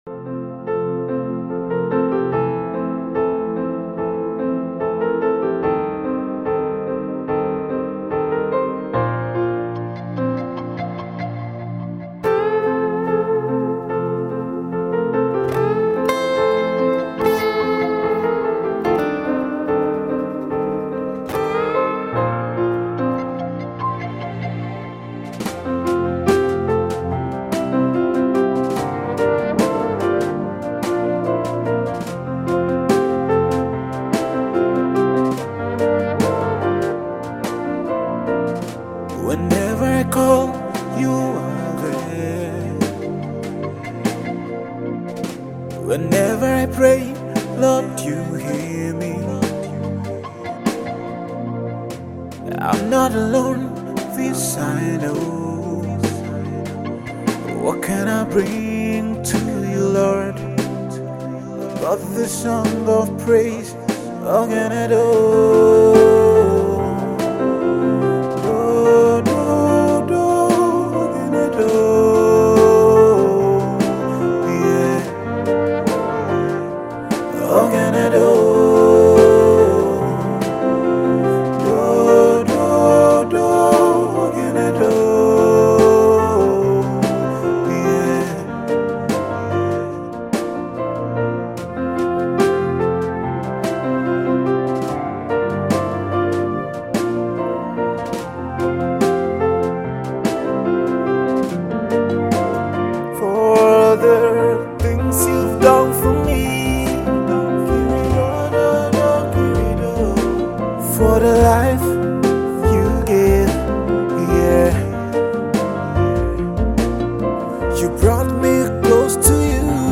a Nigerian gospel musician
rousing praise song